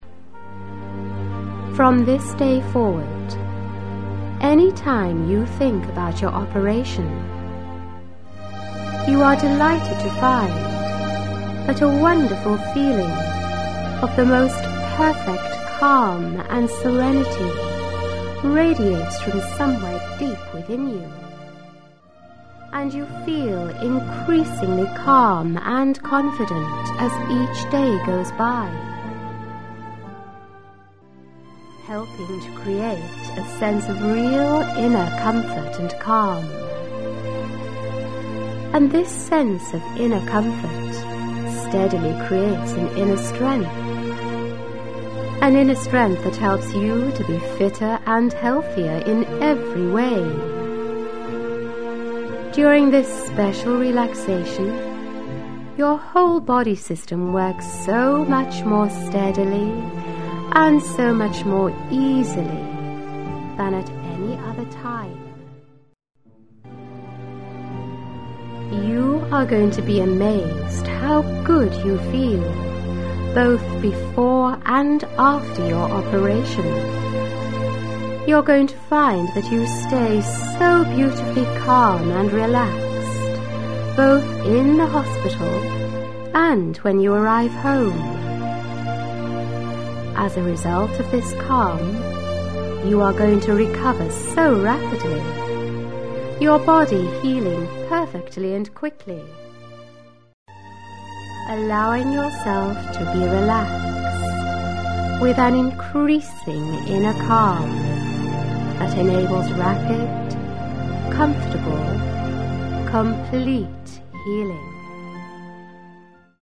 The background music we use on each recording is especially scripted at 60 beats per minutes to ensure deep relaxation.